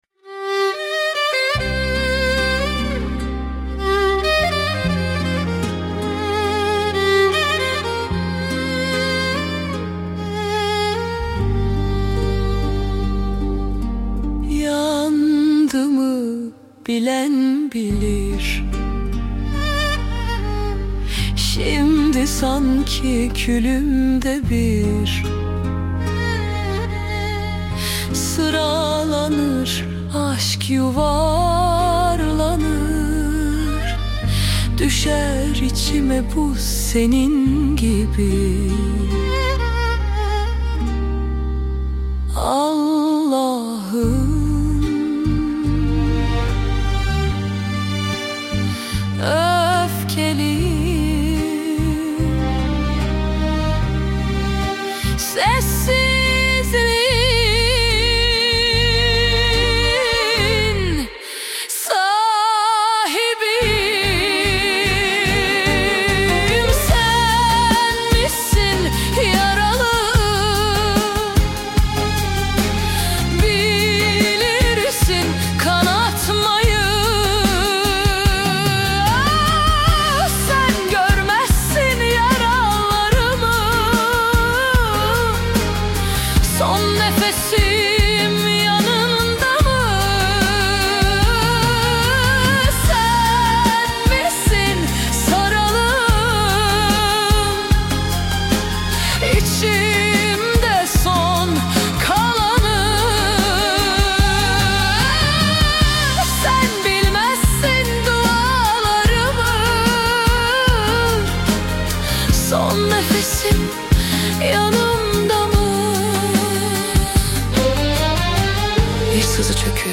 Tür : Alaturka Pop